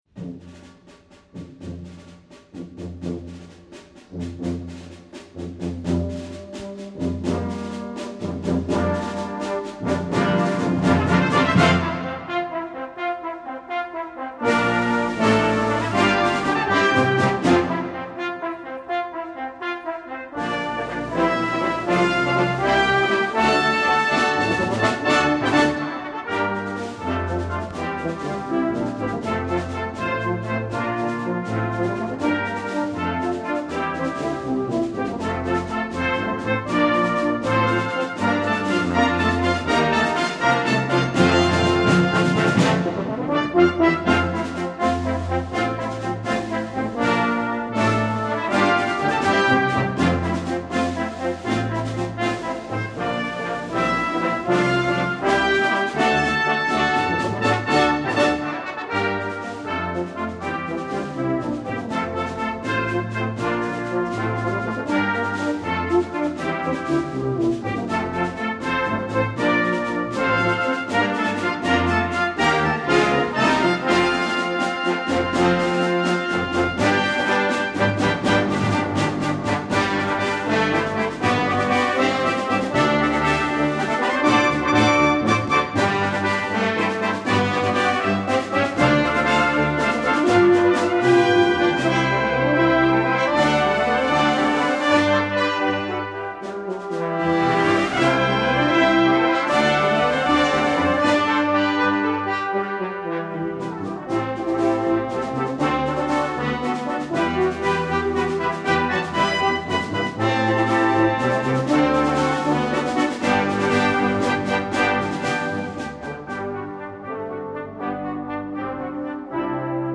Composition Style: Song Setting